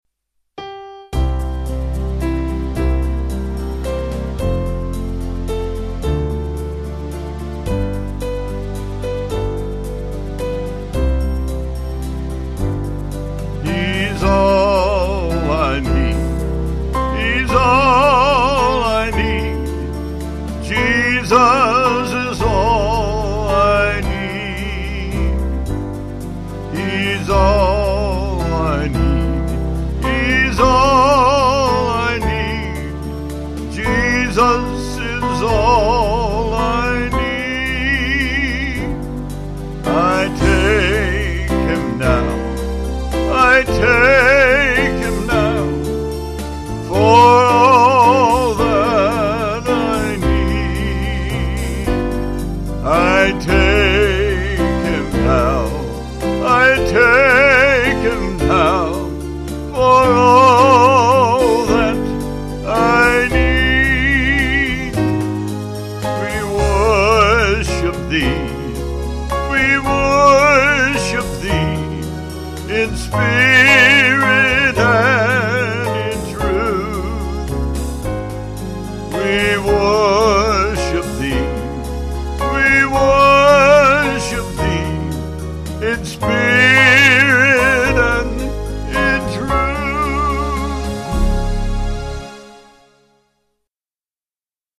(CM)   3/C-Db
Vocals & Band